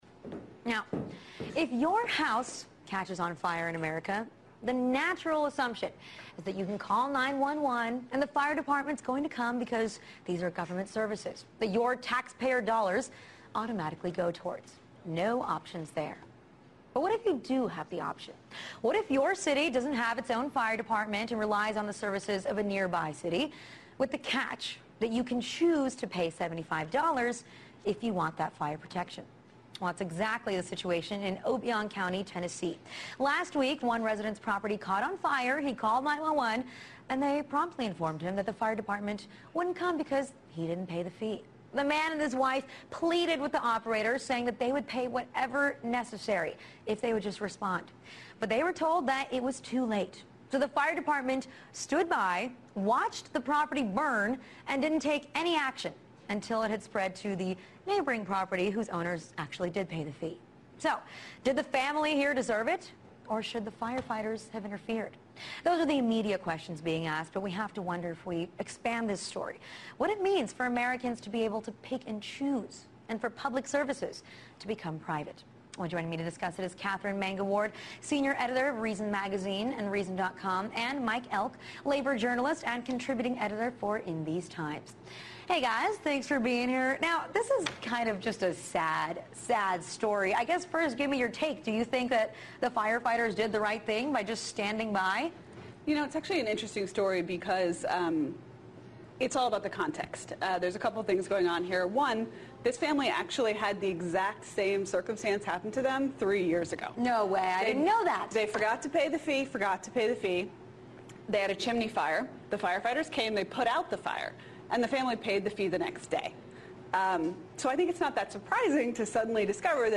argues that people should be able to pick and choose (and pay for) the services they want, including fire protection, on Russia Today's The Alyona Show on October 5, 2010.